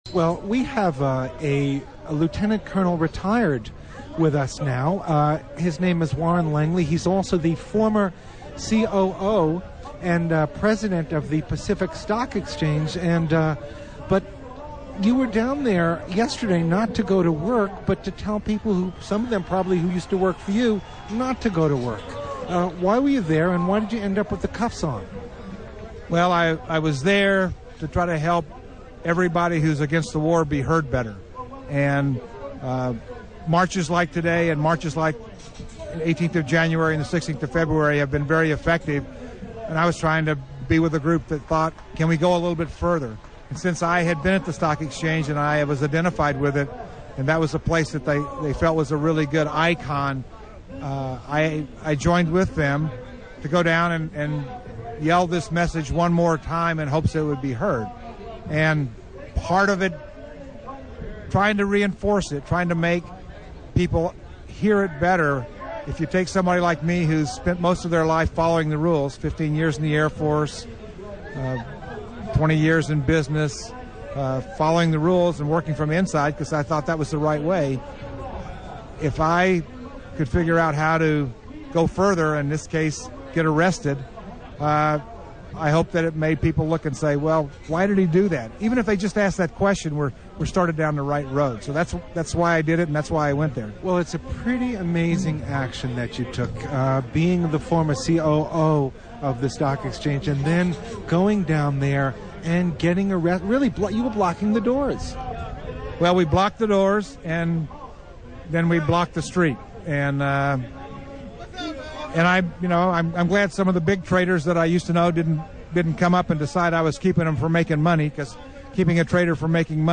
Interview about his civil